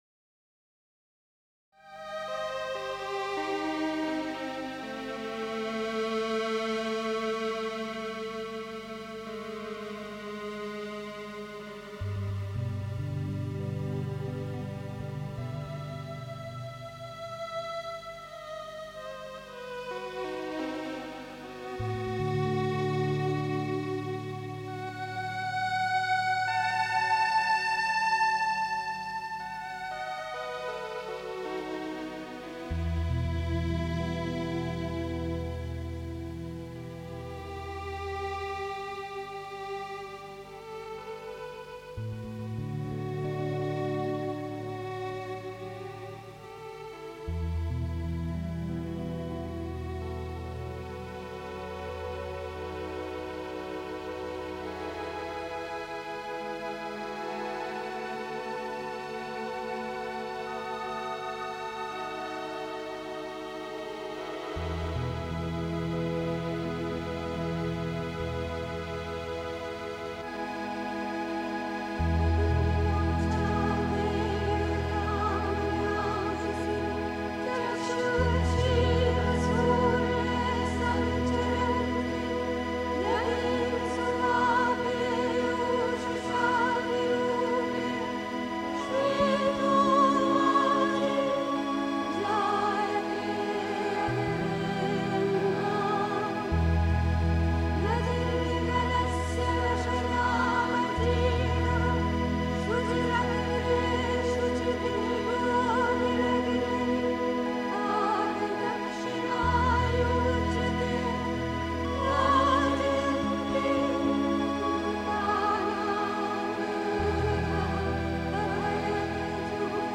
Finde Zuflucht im Göttlichen (Die Mutter, White Roses, 17 December 1955) 3. Zwölf Minuten Stille.